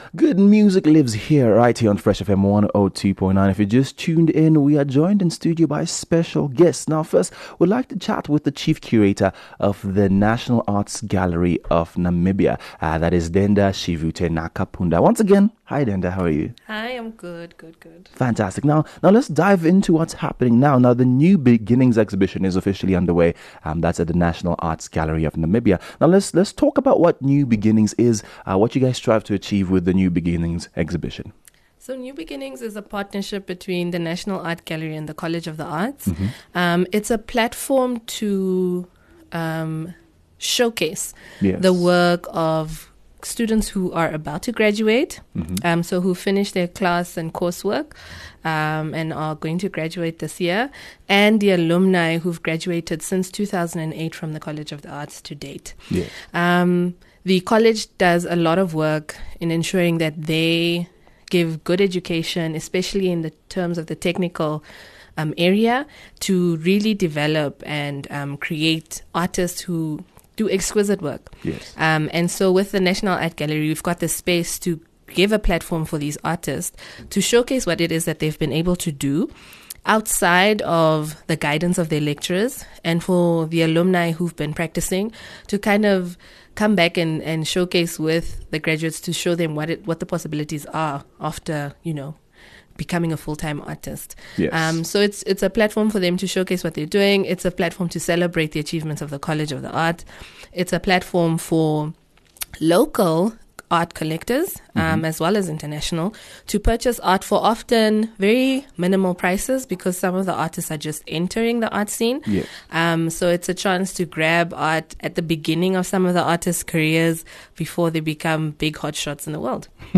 chat about the annual graduate exhibition New Beginnings by College of the Arts